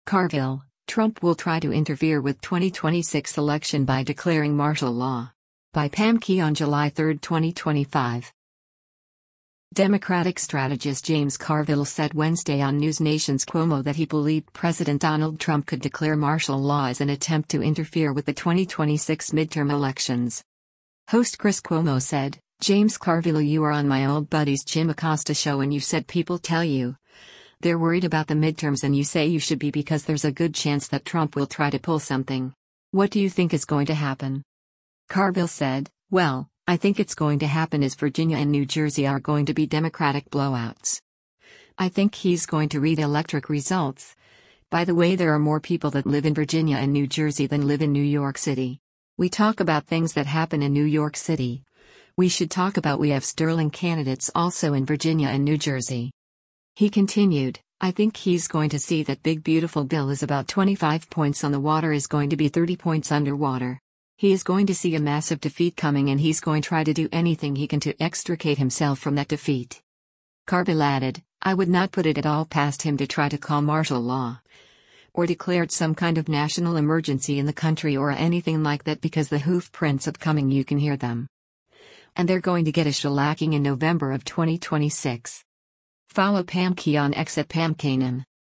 Democratic strategist James Carville said Wednesday on NewsNation’s “Cuomo” that he believed President Donald Trump could declare martial law as an attempt to interfere with the 2026 midterm elections.